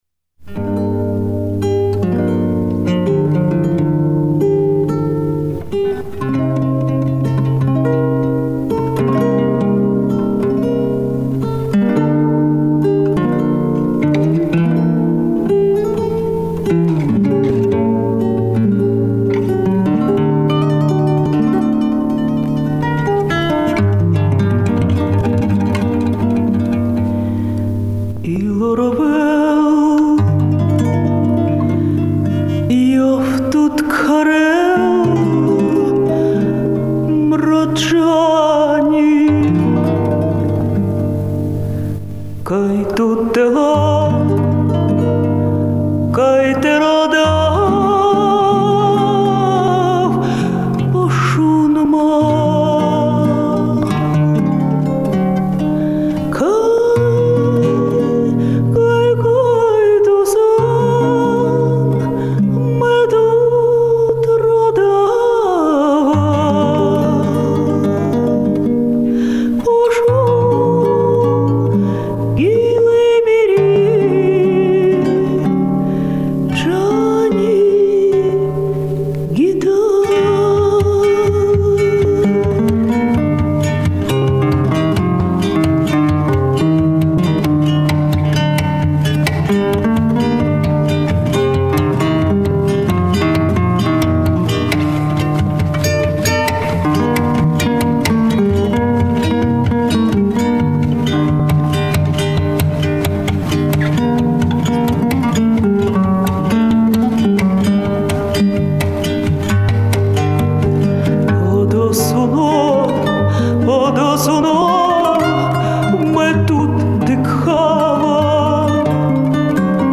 цыганская певица